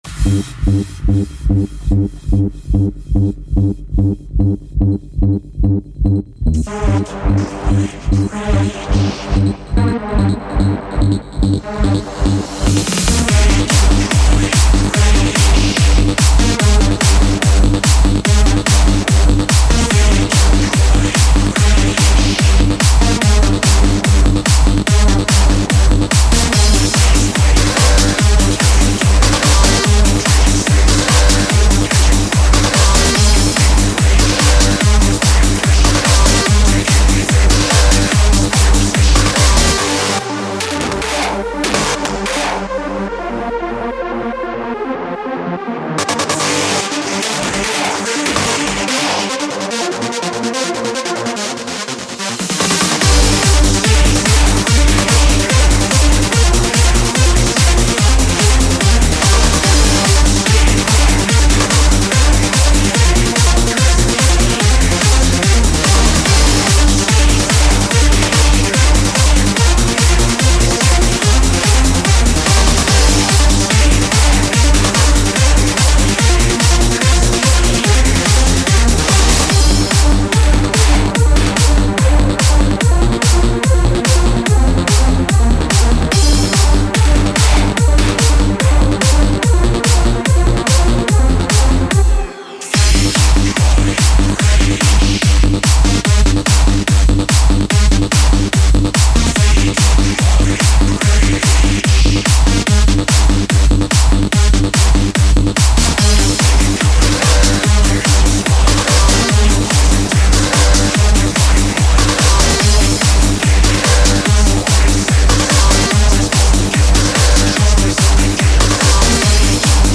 The newest in industrial, gothic, synthpop, post-punk, and shoegaze music, requests, the silly question Download this podcast